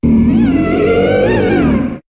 Cri de Banshitrouye Taille Ultra dans Pokémon HOME.